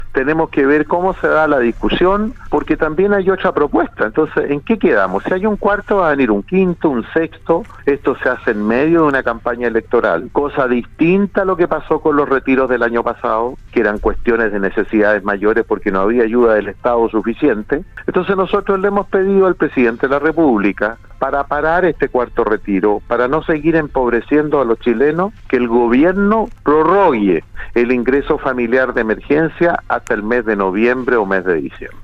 En conversación con Radio Sago, el Senador por la región de Los Lagos, Iván Moreira, se refirió a la discusión en torno al cuarto retiro del 10 por ciento de los fondos de pensiones y a las declaraciones que realizó el candidato presidencial de Chile Vamos al respecto. El parlamentario de la UDI, manifestó que hace falta extender el IFE Universal, más que ahondar en un nuevo retiro de los ahorros.